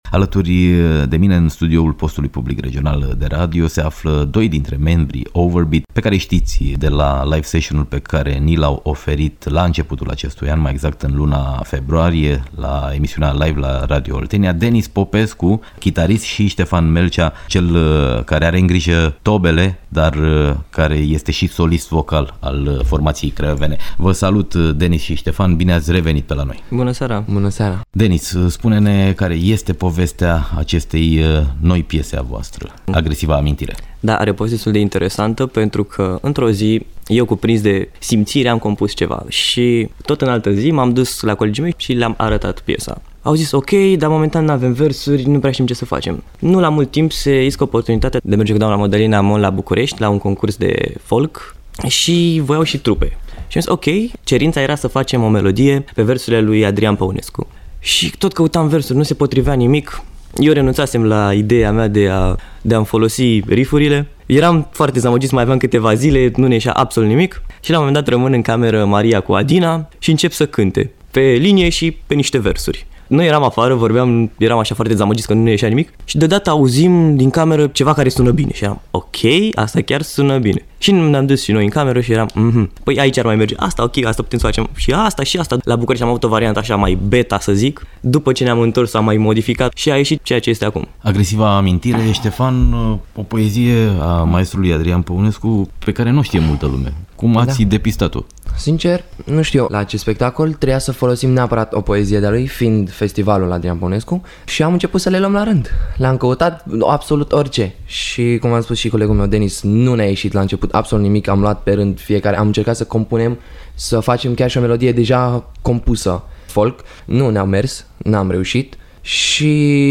Interviu cu trupa Overbeat